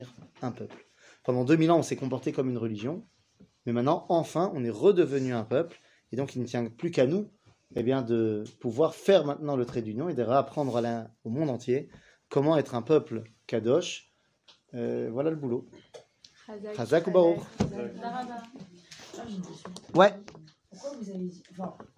שיעור מ 13 נובמבר 2022